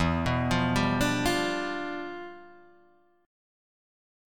Em7b9 Chord